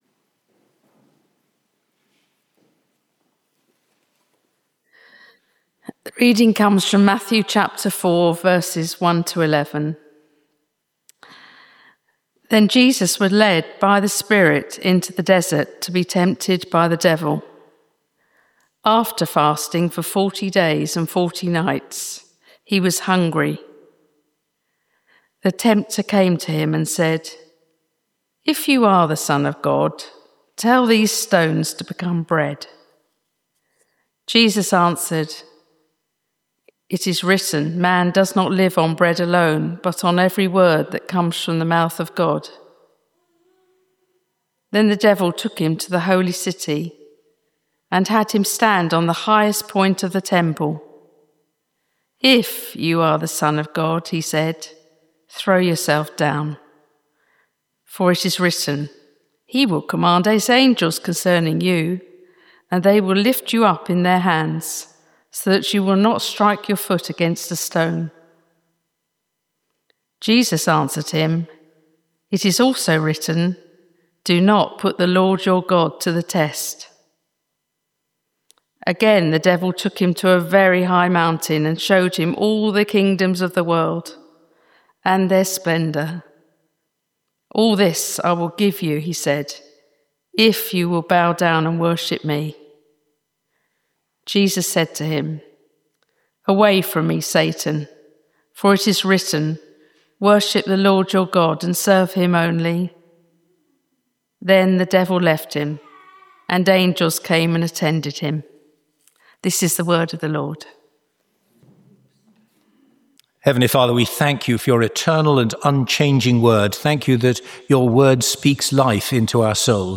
Service Type: Informal Praise